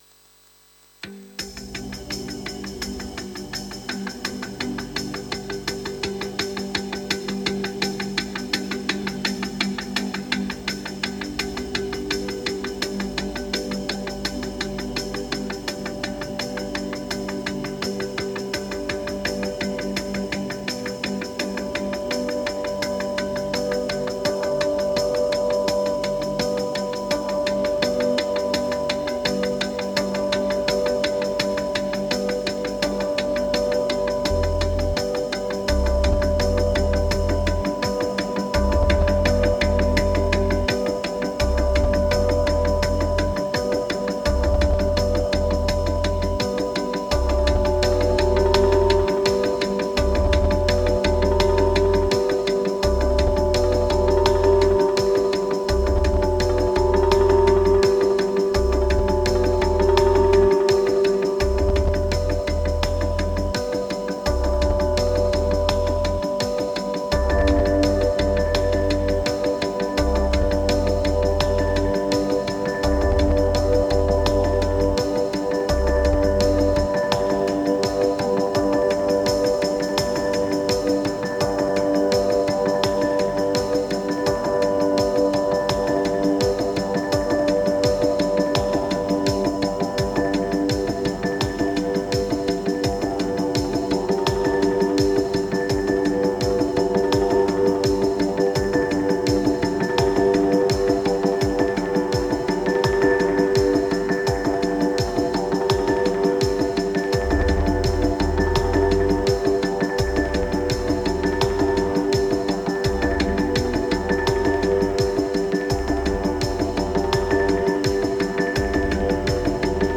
1529📈 - 77%🤔 - 84BPM🔊 - 2023-02-09📅 - 459🌟
Ambient Electro Progress Transport Inner Draft Moods